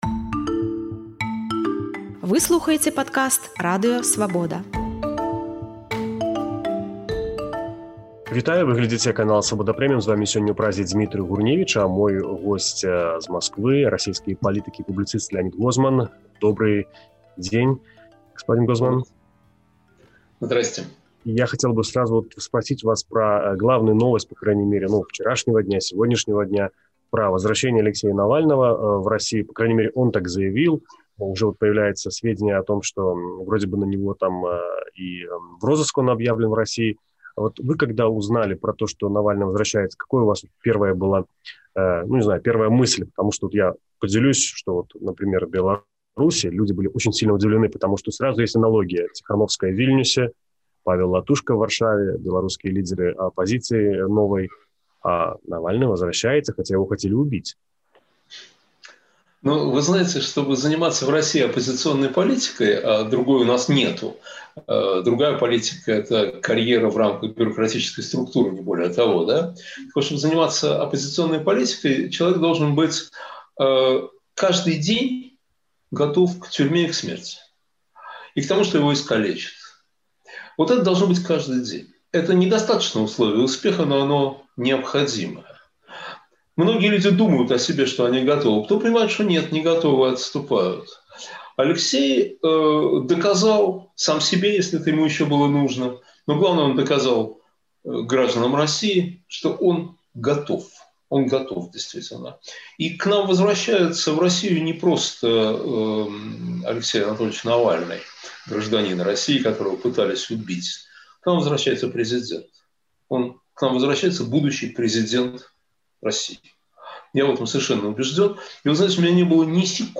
Маскоўскі публіцыст і лібэральны палітык Леанід Гозман расказвае ў інтэрвію «Свабодзе», чаму лічыць Аляксея Навальнага будучым прэзыдэнтам Расеі, і тлумачыць, чаму беларусам ня варта баяцца Навальнага ў Крамлі.